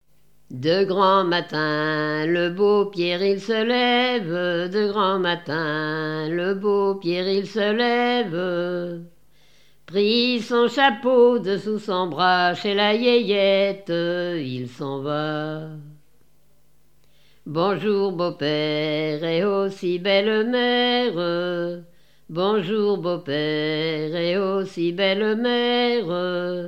Interprétation de chansons à partir d'un cahier de chansons
Pièce musicale inédite